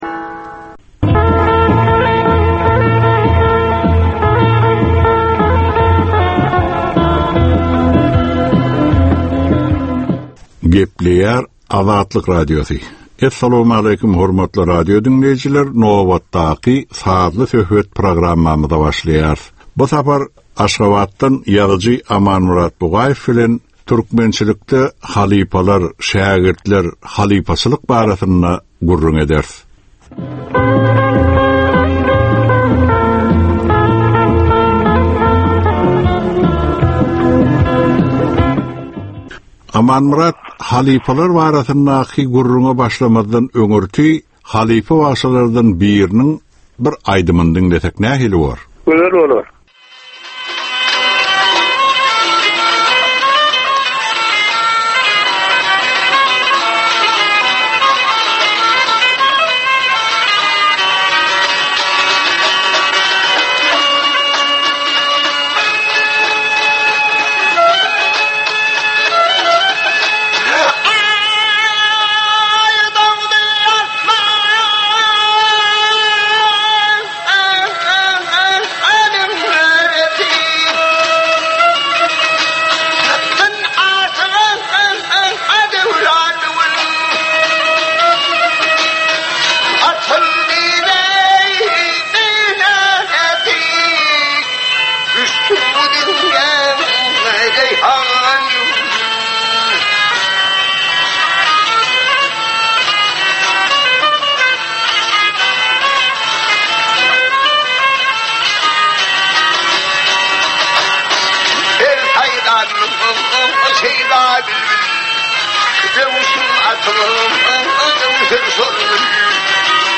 Türkmenin käbir aktual meseleleri barada 30 minutlyk sazly-informasion programma